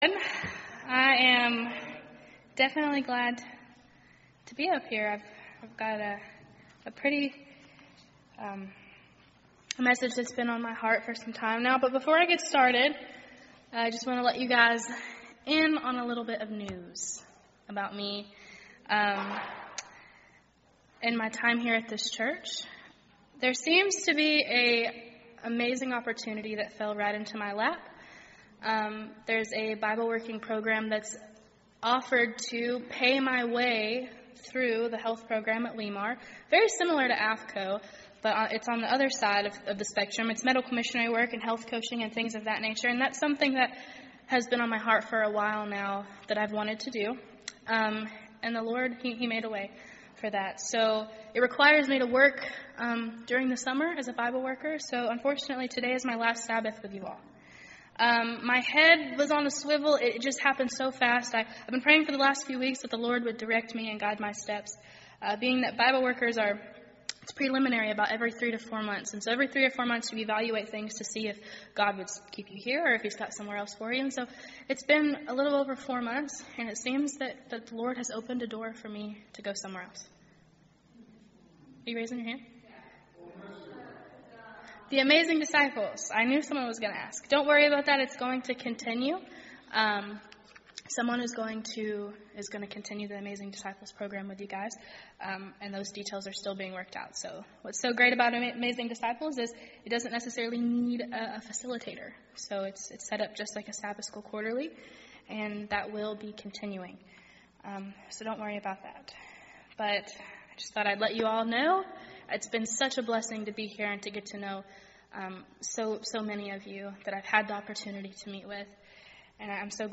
Sermons .